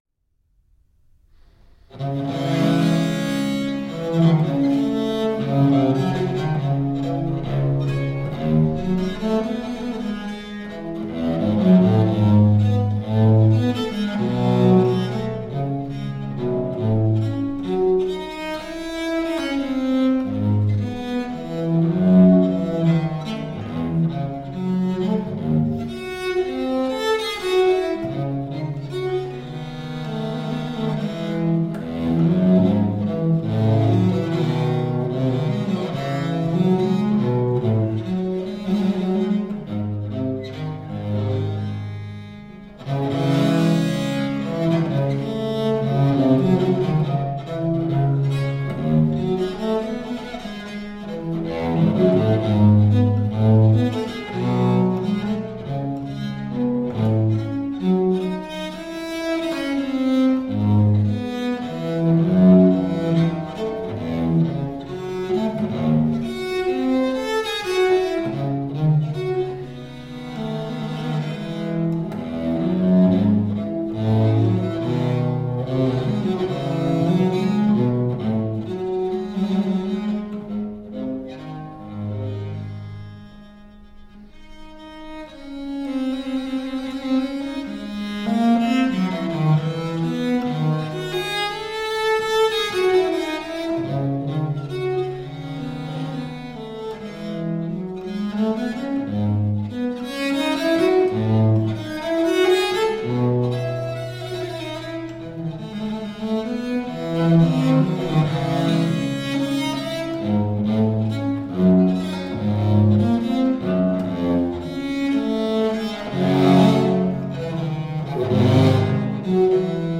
Virtuoso viola da gamba.
Classical, Baroque, Renaissance, Instrumental